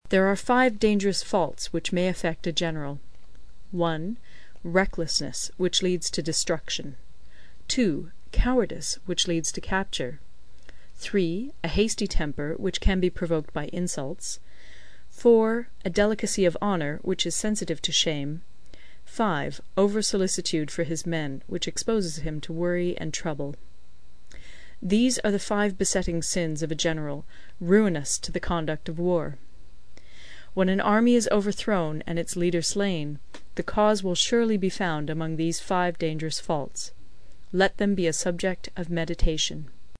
有声读物《孙子兵法》第49期:第八章 九变(5) 听力文件下载—在线英语听力室